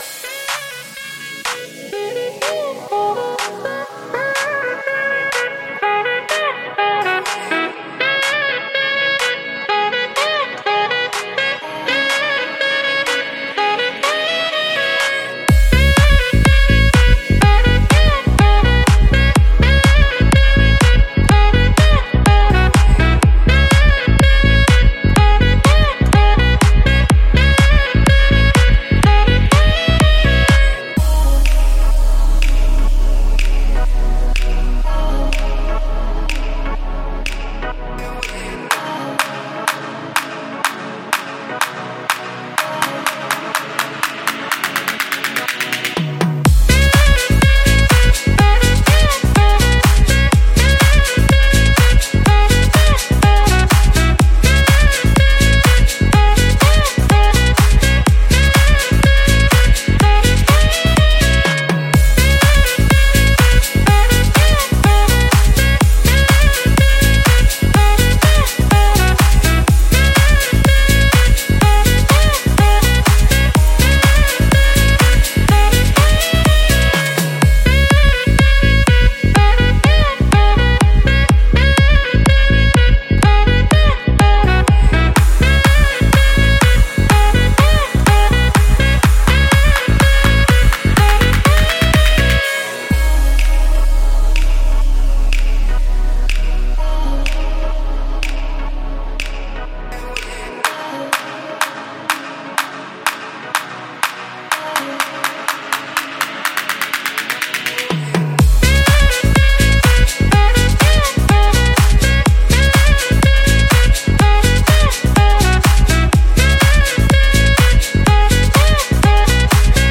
Saxophone
آهنگ ساکسفون آرامبخش و ملایم